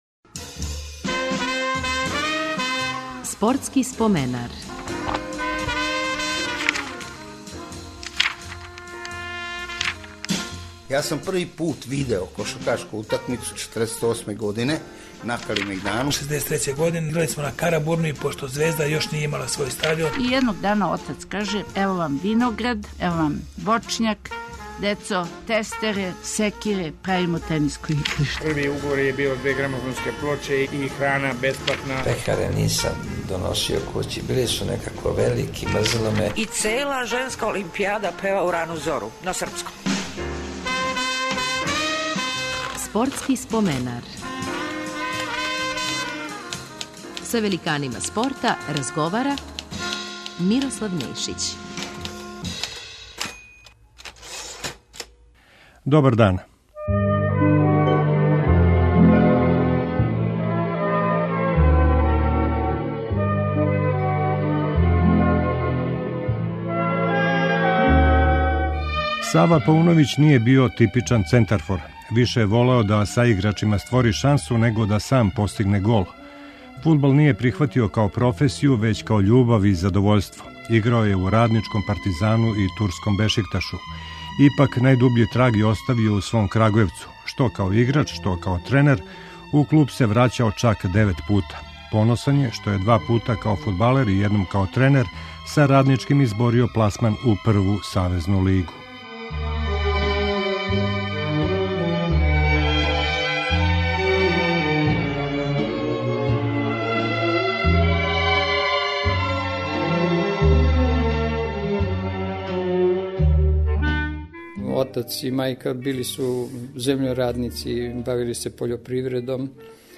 Радио Београд 1 од 16 до 17 часова.